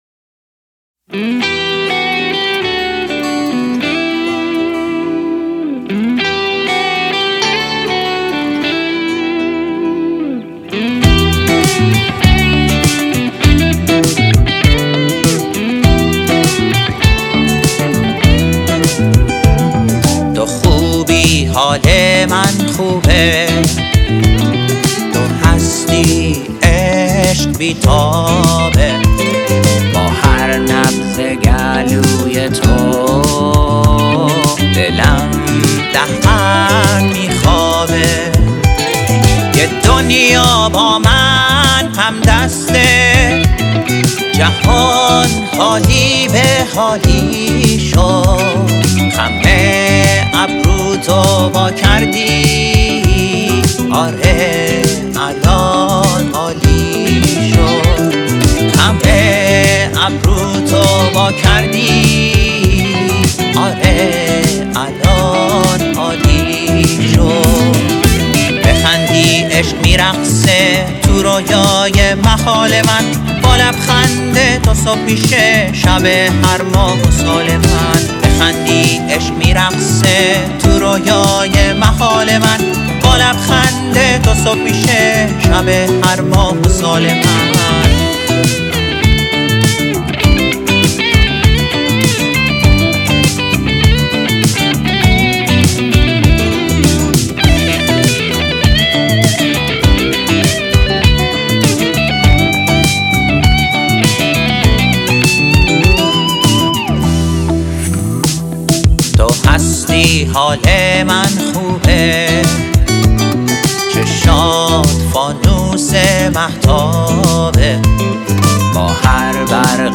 🔸میکس و گیتار بیس
🔸گیتار